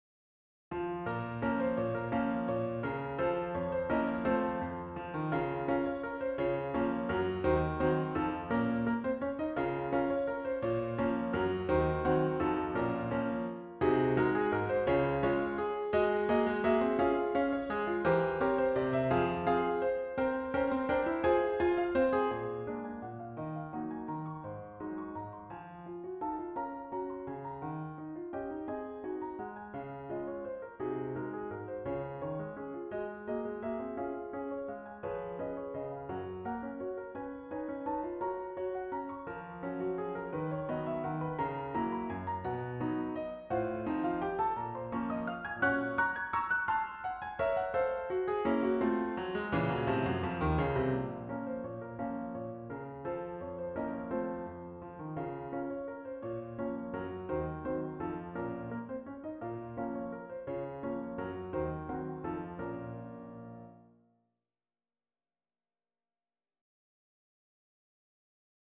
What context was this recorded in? The recording was realized by the Garritan Piano.